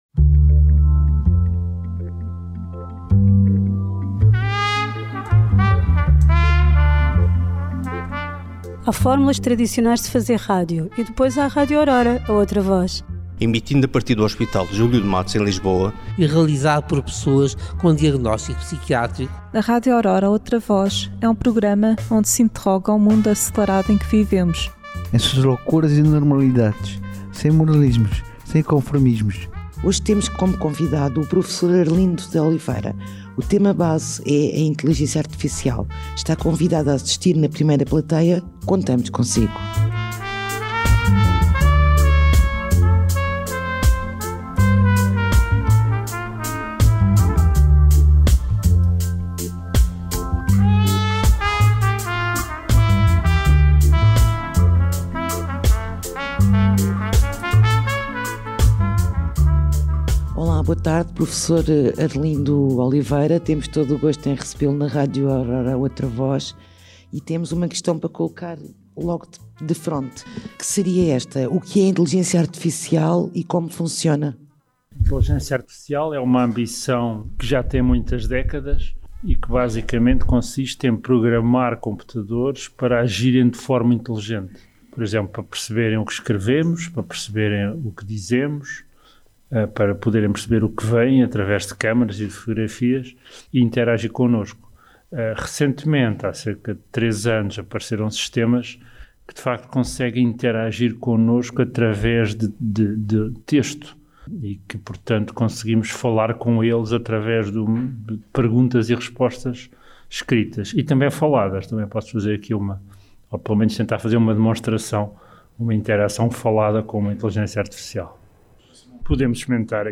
Primeira parte da entrevista